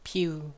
snd_fire.ogg